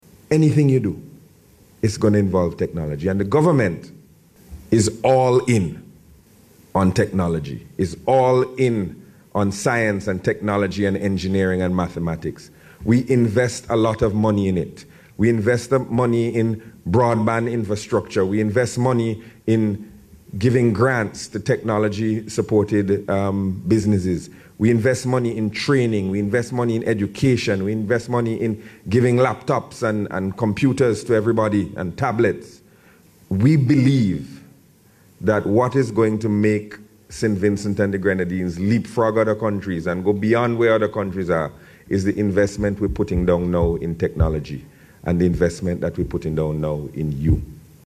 Also addressing the ceremony was Minister of Information Technology Camillo Gonsalves, who restated the Government’s commitment to investing in Technology.